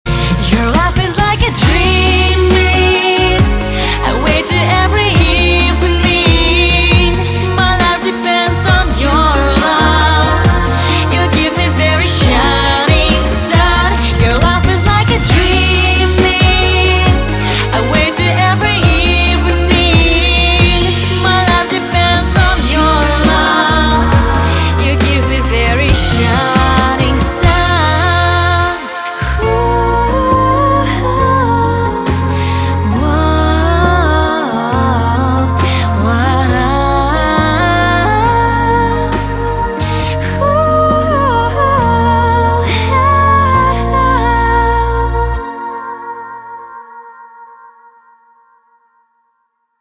• Качество: 320, Stereo
романтичные